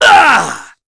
Chase-Vox_Damage_03.wav